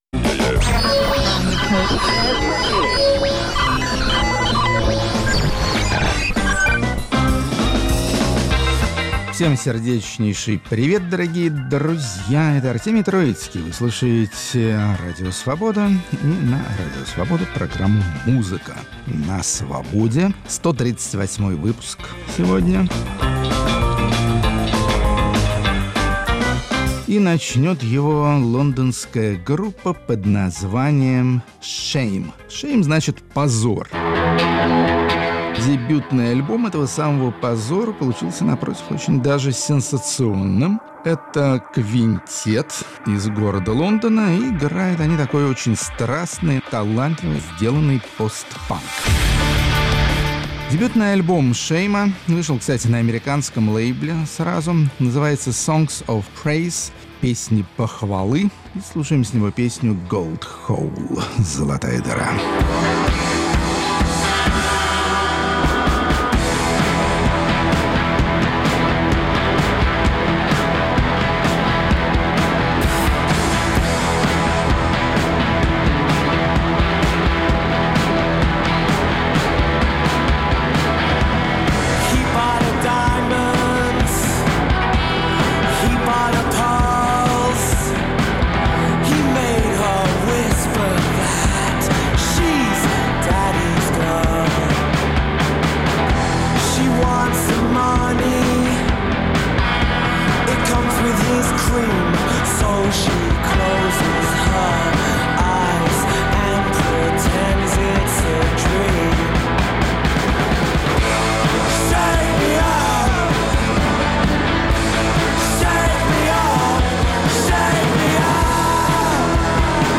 Музыка на Свободе. 8 декабря, 2019 Музыканты из союзной России Беларуси, которые неизменно присутствовали и присутствуют на отечественной рок-сцене, а в последние десятилетия органично и незаметно покоряют и европейско-американскую. Рок-критик Артемий Троицкий отделяет белорусское от русского и представляет белорусские песни.